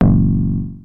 描述：FM清扫车2
Tag: FM收音机 合成器 低音 模块化